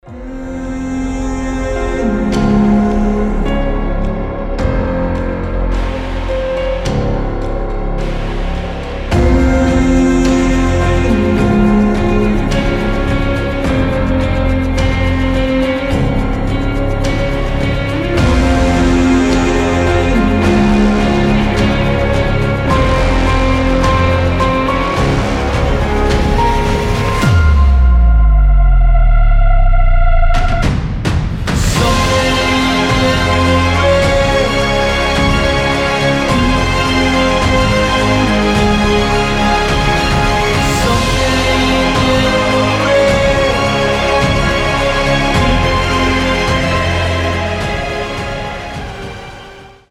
атмосферные
нарастающие
оркестр
эпичные
тревога